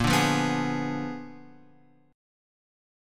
A# 7th Flat 9th